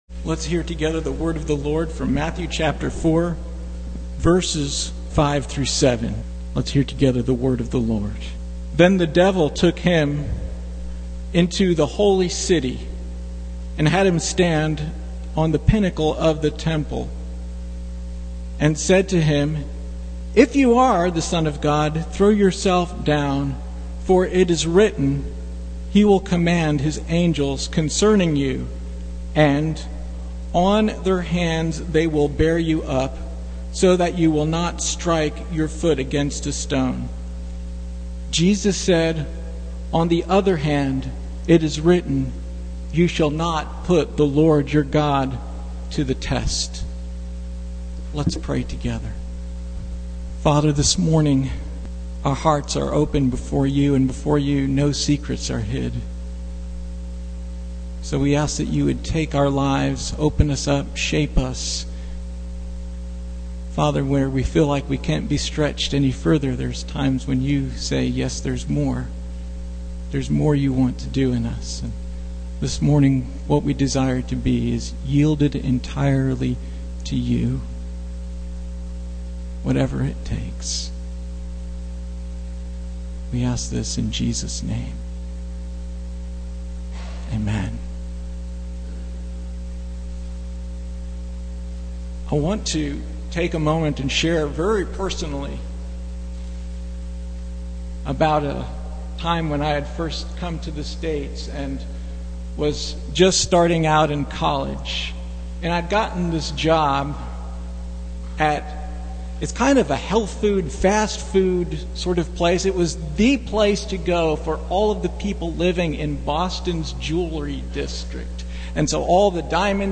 Passage: Matthew 4:5-7 Service Type: Sunday Morning